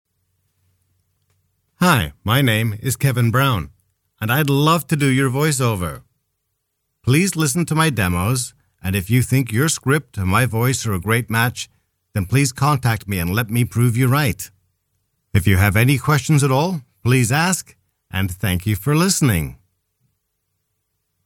外籍110男-自我介绍- 磁性 干音